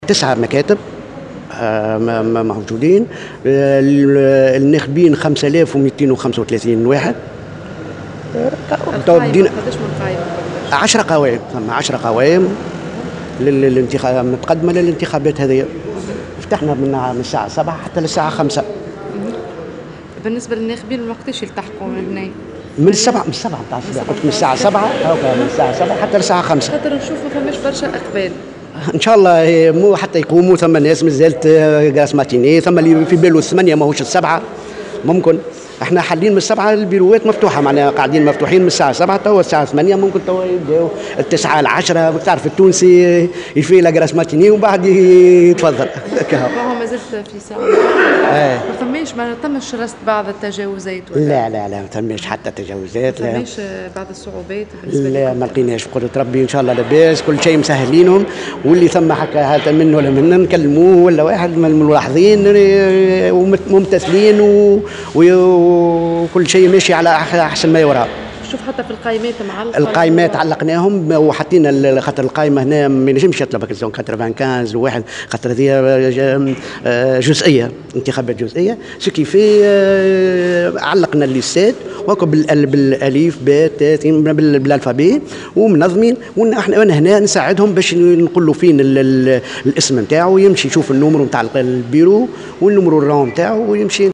وقال في تصريح لمراسلة "الجوهرة اف أم" إنه تم فتح المكاتب منذ الساعة السابعة صباحا وتتواصل عملية الاقتراع إلى غاية الخامسة مساء. وأشار إلى أن عدد الناخبين المسجلين يبلغ 5235 ناخبا لانتخاب المترشحين من خلال 10 قائمات انتخابية متقدمة لهذه الانتخابات الجزئية، كما يضمّ المركز 9مكاتب للاقتراع.